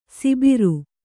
♪ sibiru